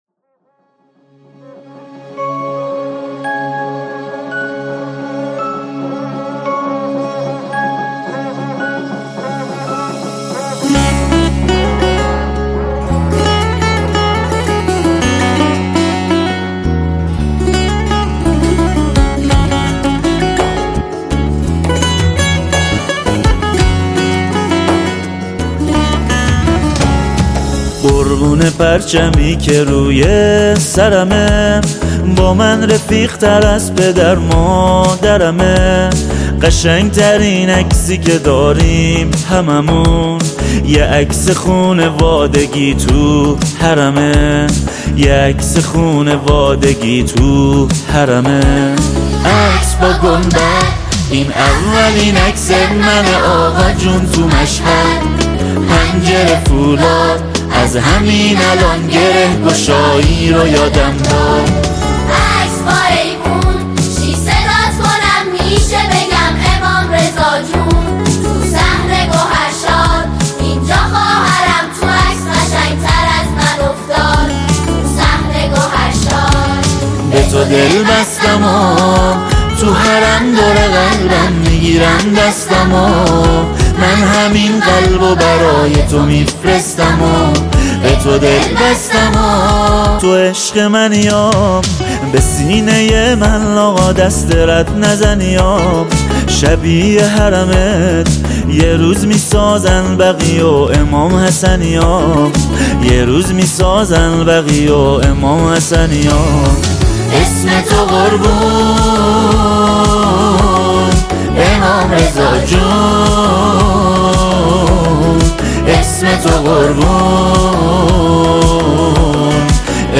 ژانر: سرود ، سرود مناسبتی